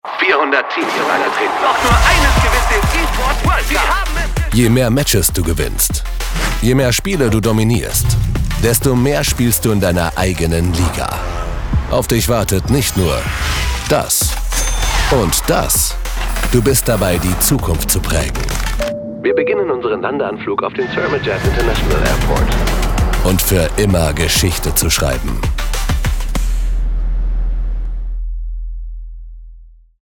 Male
Approachable, Assured, Authoritative, Bubbly, Character, Confident, Conversational, Cool, Corporate, Deep, Energetic, Engaging, Friendly, Funny, Gravitas, Natural, Posh, Reassuring, Sarcastic, Smooth, Soft, Streetwise, Upbeat, Versatile, Wacky, Warm, Witty
Commercial Brandvoice.mp3
Microphone: Tlm 103, SA87, Aston Spirit